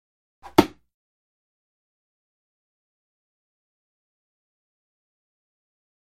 Звуки дротиков
Короткое расстояние